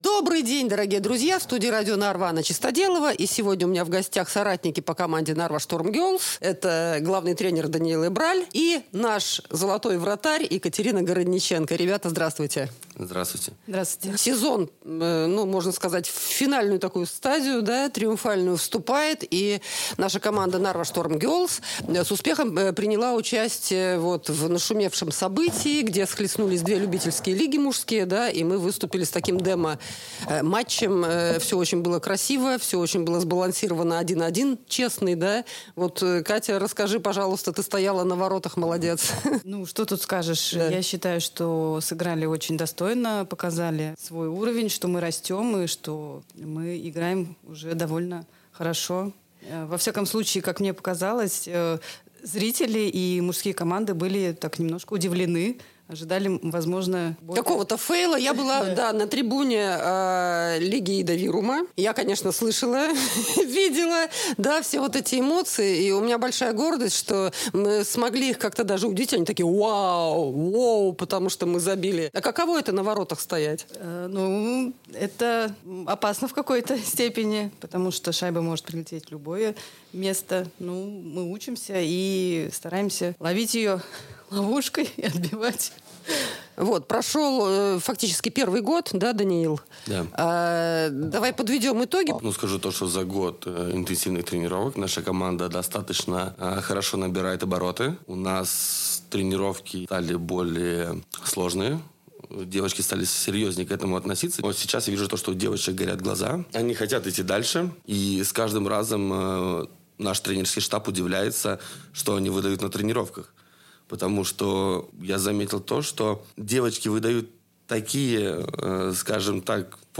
в эфире Radio Narva